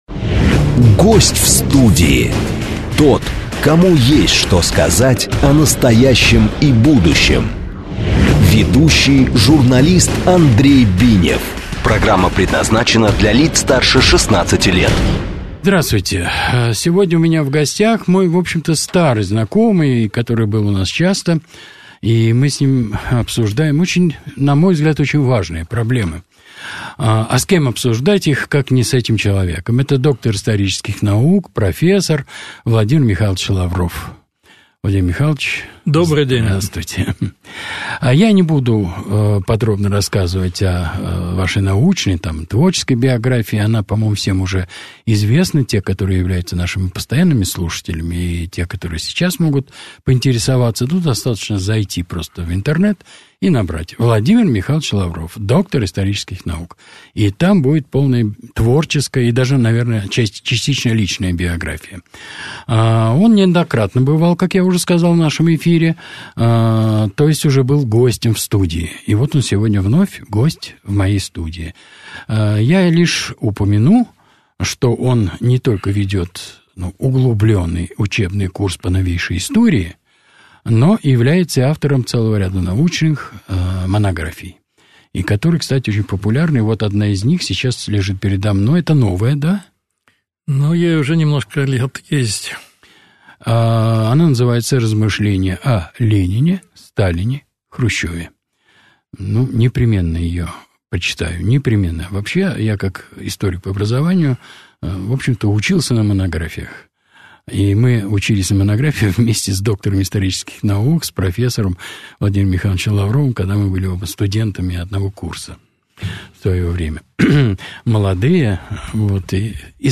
Гость в студии (16+) 2025-04-20